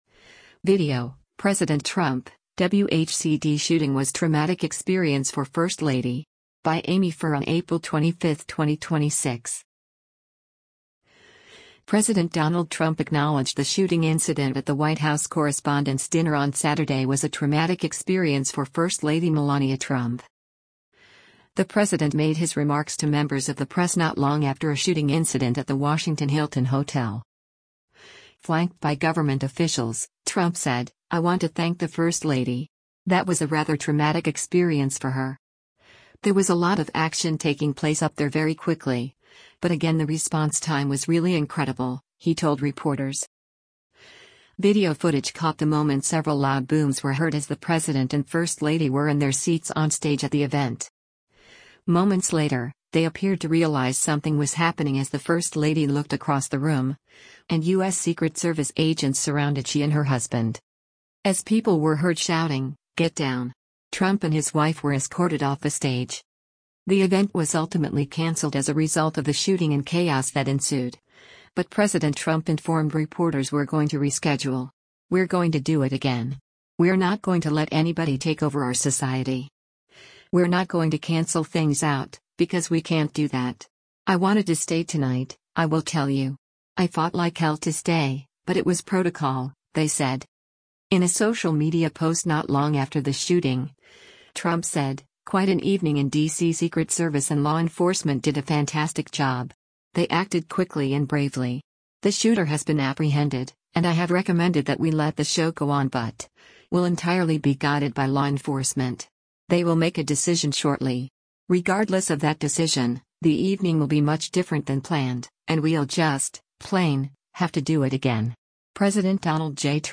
The president made his remarks to members of the press not long after a shooting incident at the Washington Hilton hotel.